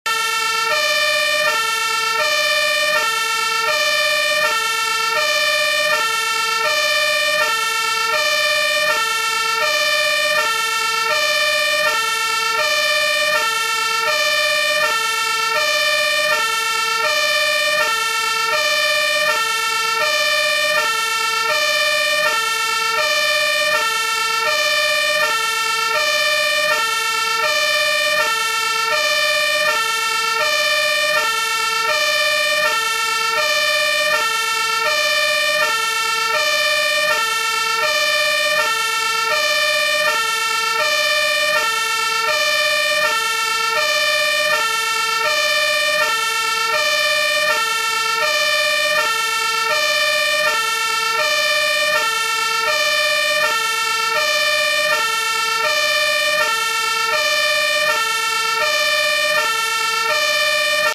Feuerwehr - Bouton d'effet sonore